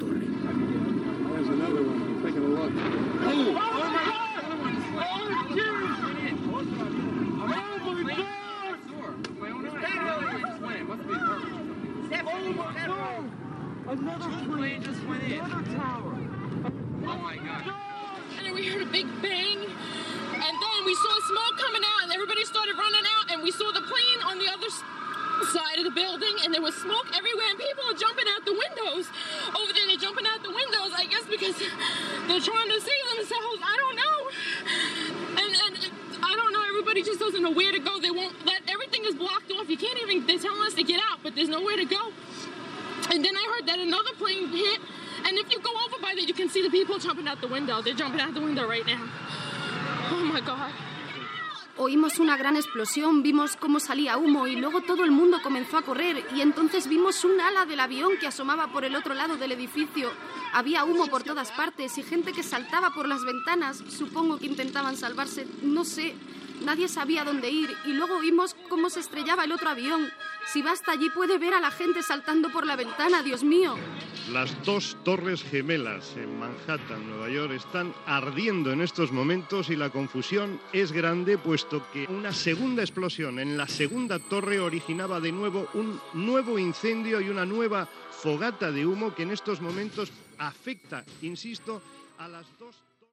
So ambient a la zona del World Trade Center de Nova York, declaració d'una de les persones que ha pogut sortir d'una de les torres bessones i descripció de la situació després de l'atemptat
Informatiu